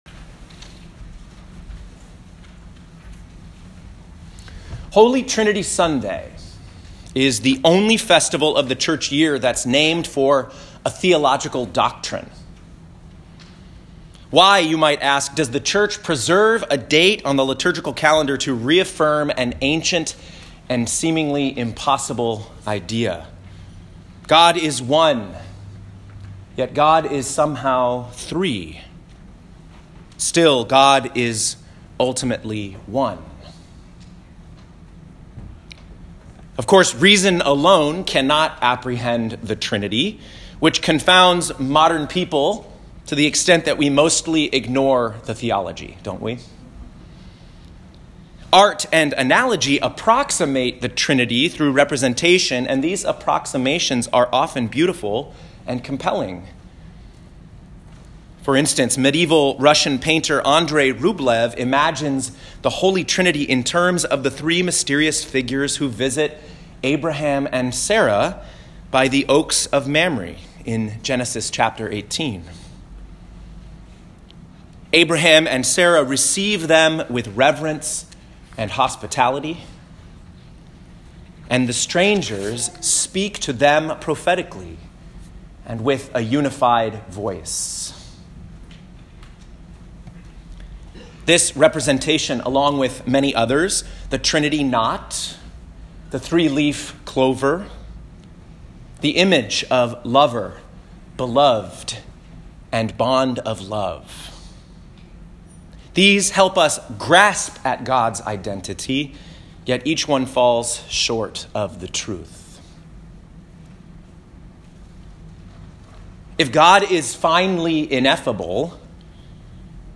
Home › Sermons › Glimpsing the Truth